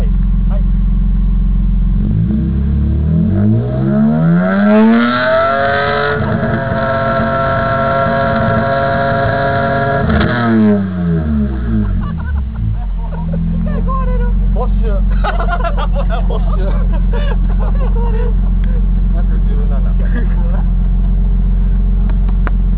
排気音測定オフ？